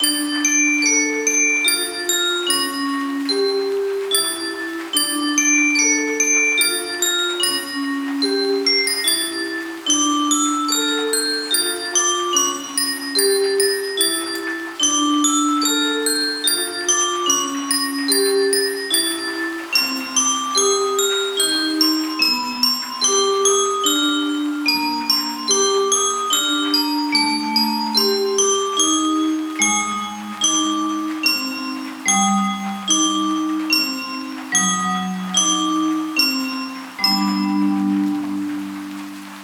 Loop